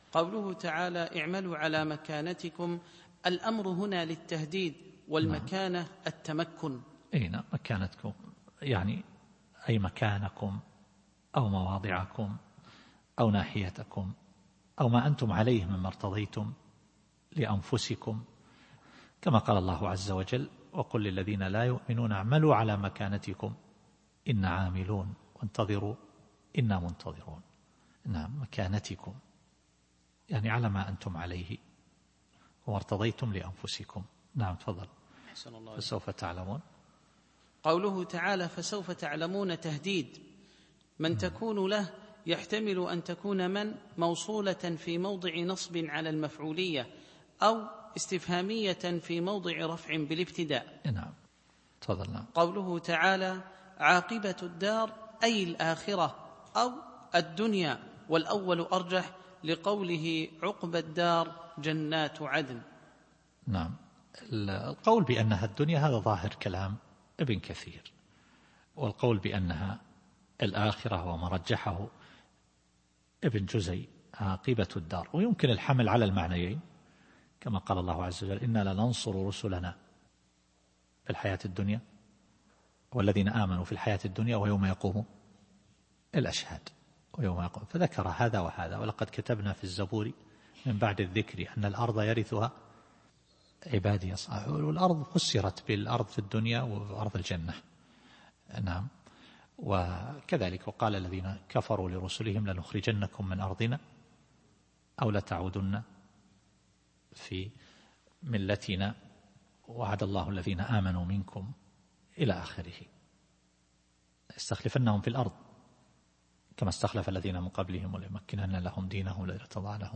التفسير الصوتي [الأنعام / 135]